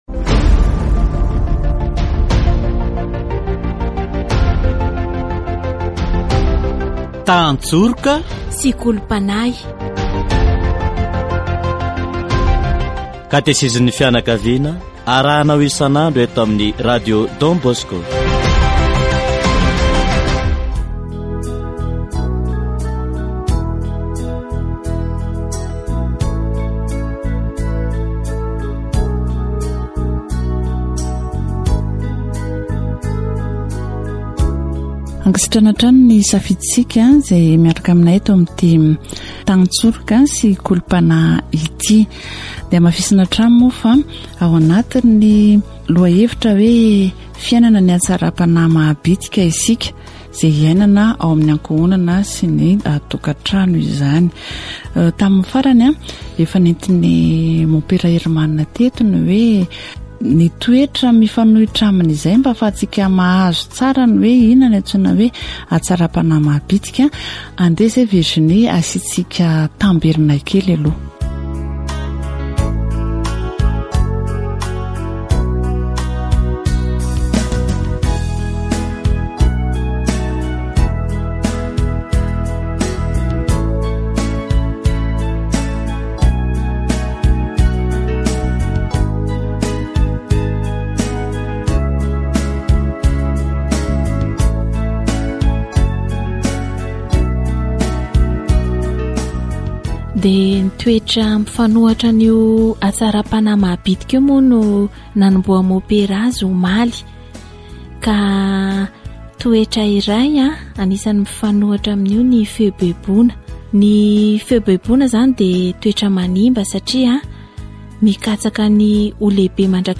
It is important to learn to have the virtue of littleness because it comes from God. It is important to ask God through prayer to give us this goodness. Catechesis on The Virtue of Smallness